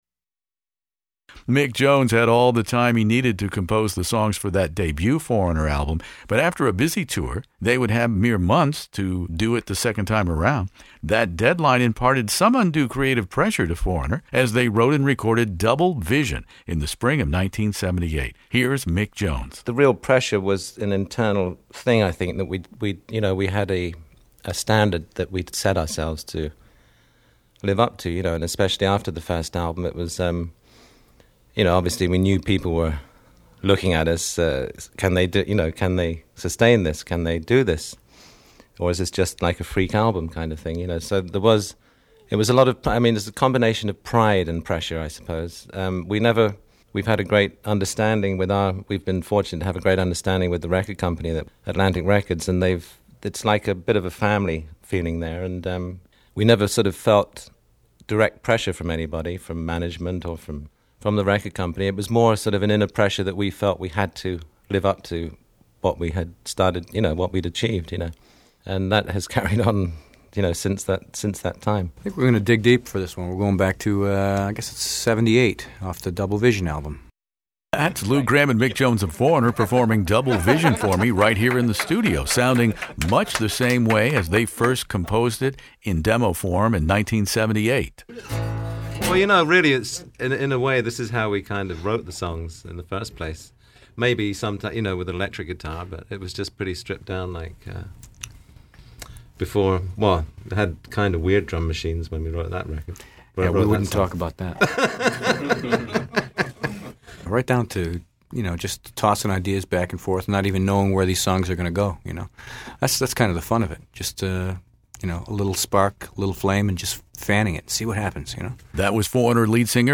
foreigner-interview-double-vision.mp3